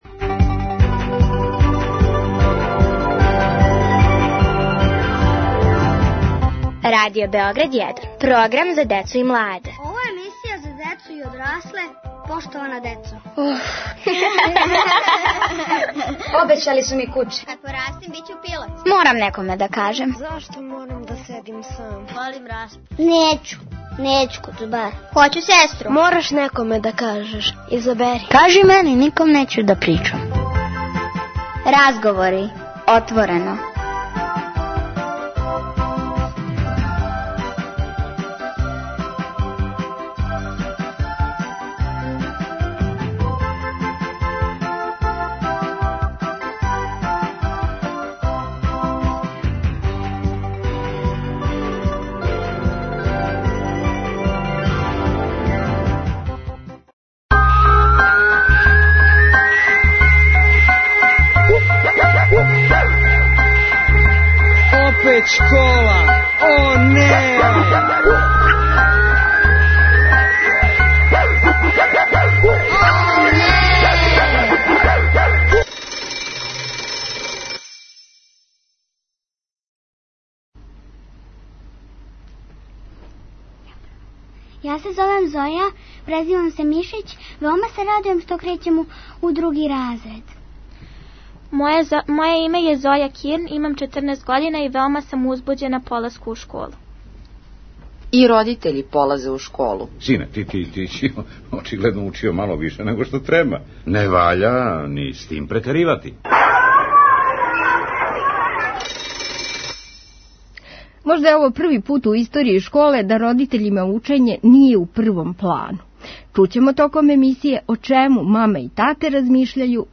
Поштована деца и поштовани наставници разговарају о новој школској години.